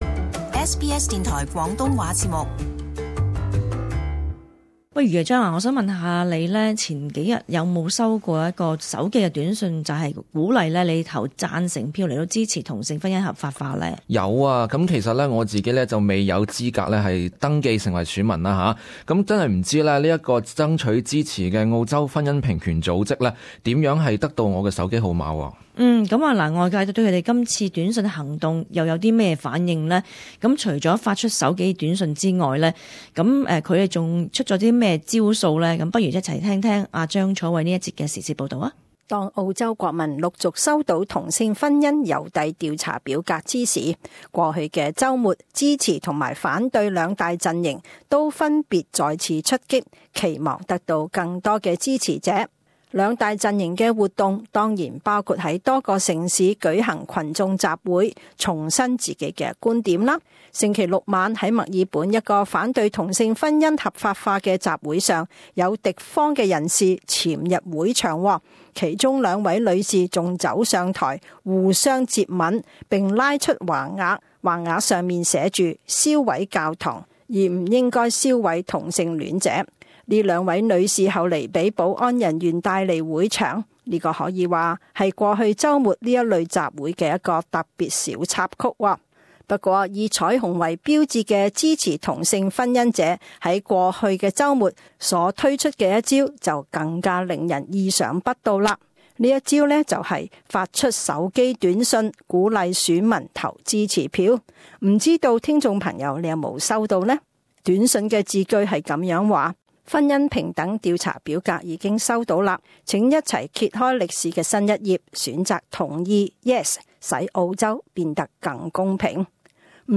【時事報導】為何他們知道我的手機號碼？